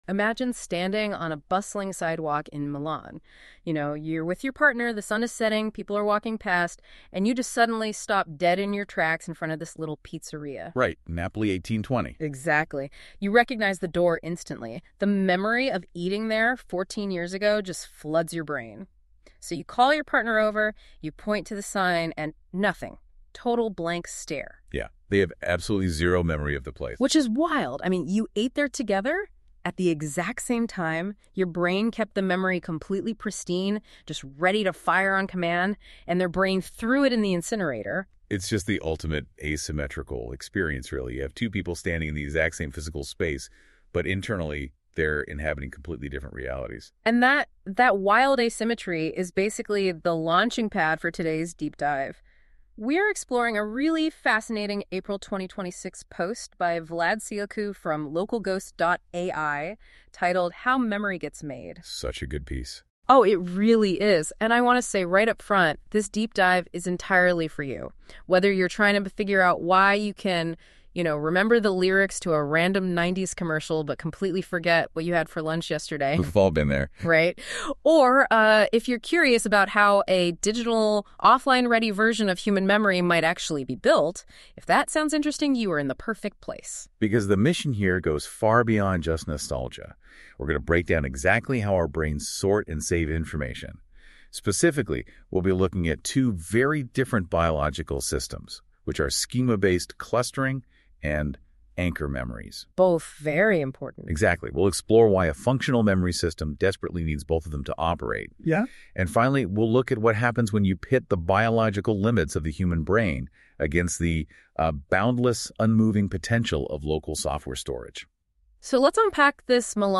> EPISODE 09 // OFFLINE-READY NOTEBOOKLM AUDIO ▶ ❚❚ 00:00 / --:-- DOWNLOAD Have you ever considered how a computer representation of your brain would work?